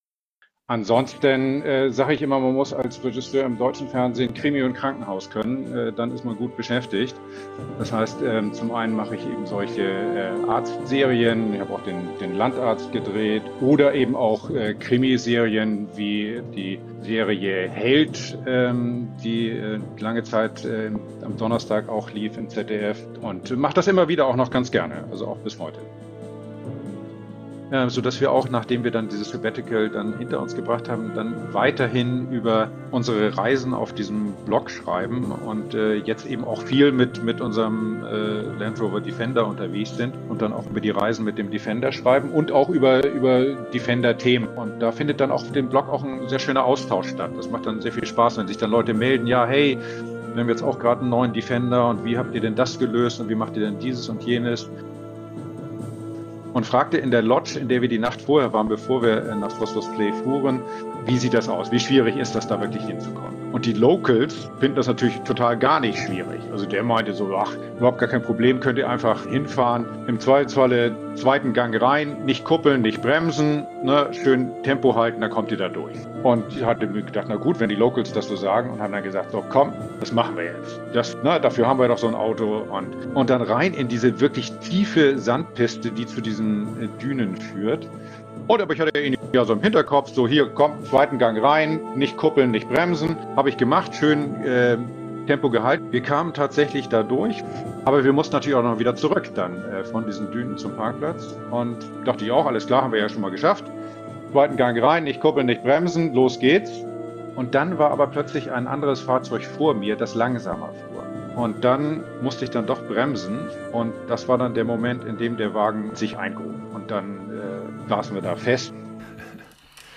Die gelegentlichen Knackgeräusche im Gespräch möchte ich schon mal im Voraus entschuldigen. Lag wohl an der schlechten Verbindung zwischen Holland und Calw.